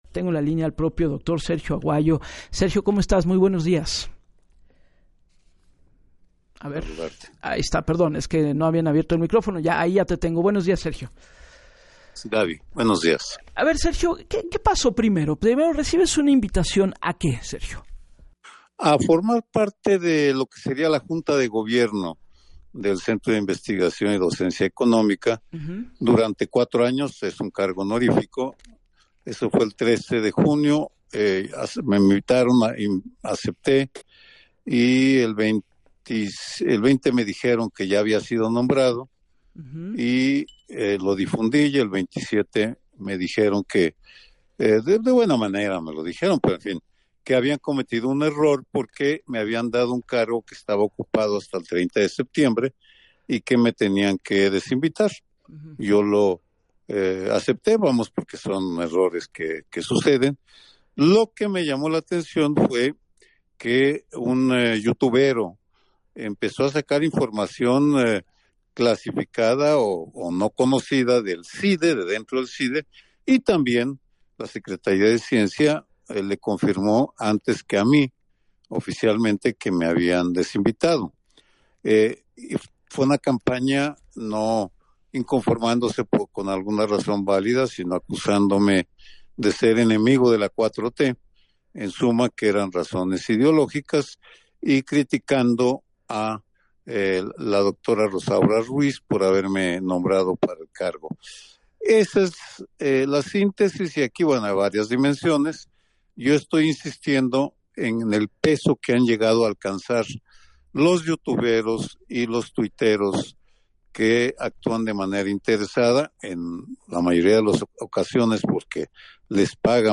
En entrevista para “Así las Cosas” con Gabriela Warkentin, detalló que luego de una invitación a formar parte de la junta de gobierno del Centro de Investigación y Docencia Económica, CIDE durante 4 años, un cargo honorífico, el pasado 13 de junio, el 20 le dijeron que ya había sido nombrado y “el 27 me dijeron que habían cometido un error que me habían dado un cargo que estaba ocupado hasta el 30 de septiembre y que me tenían que desinvitar”.